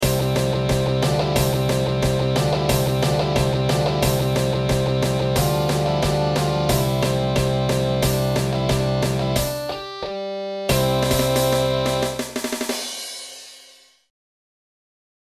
Traditional > Traditionnal - Soldiers On Parade (Rock Version)